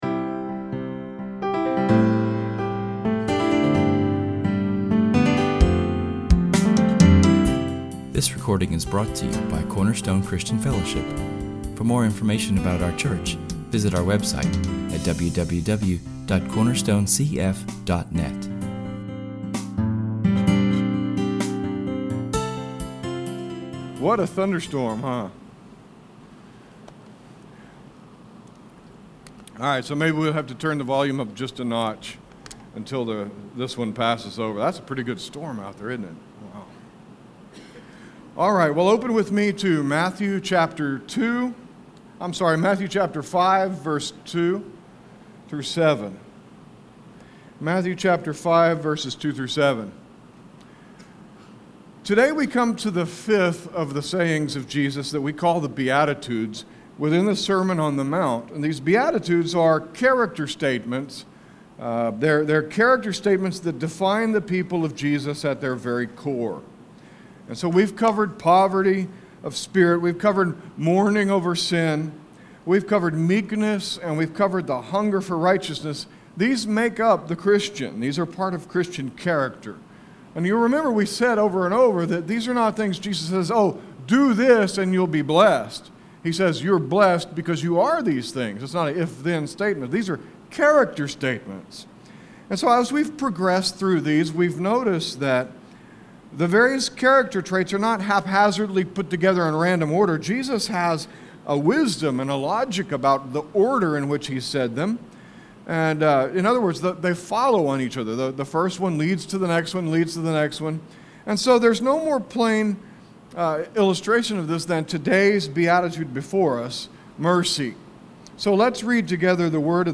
Our sermon this week comes from the fifth beatitude found in [esvignore]Matthew 5:7[/esvignore]. We will examine a lot of scripture this week as we enter “The Mercy Test.”